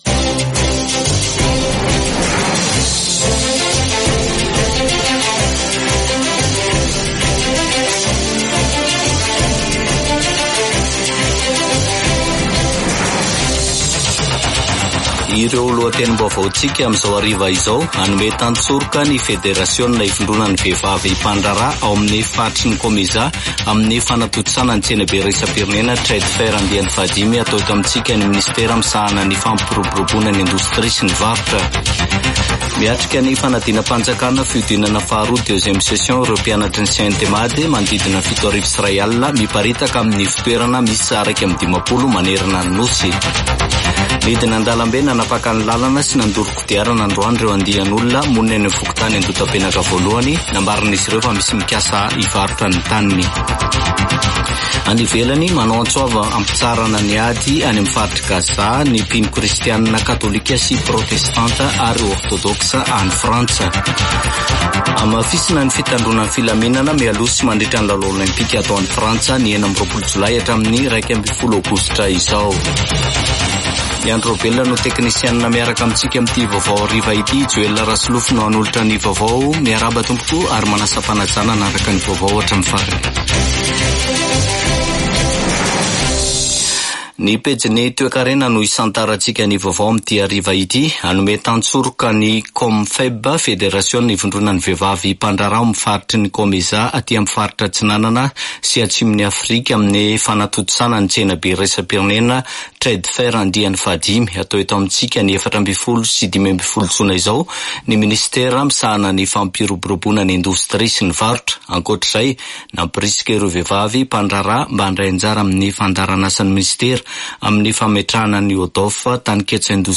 [Vaovao hariva] Alarobia 6 marsa 2024